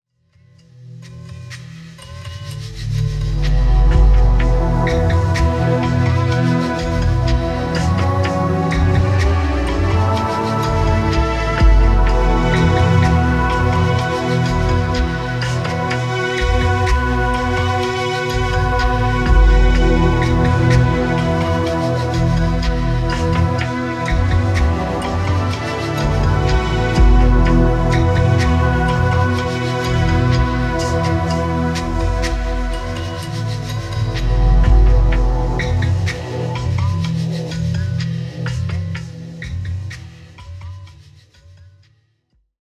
ディープなニューエイジ/アンビエント的アプローチの楽曲を展開。荘厳なモチーフと分厚いローエンドで空間を覆う